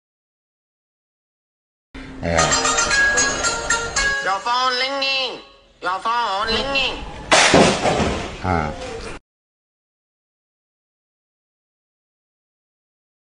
Chinese Phone Ringing Meme Sound Button: Unblocked Meme Soundboard